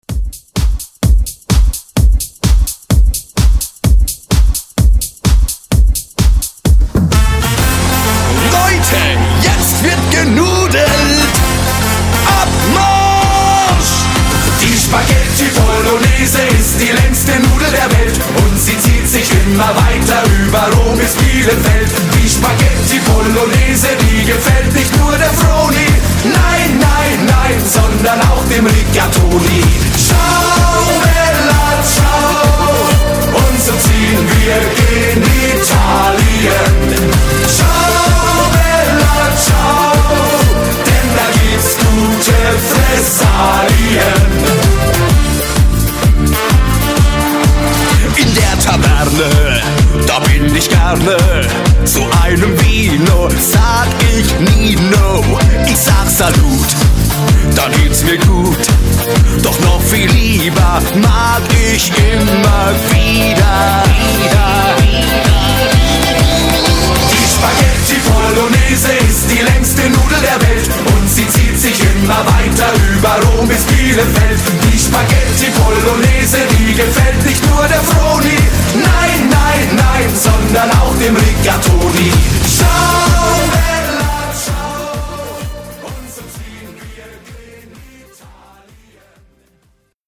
Genres: DANCE , GERMAN MUSIC , RE-DRUM
Clean BPM: 160 Time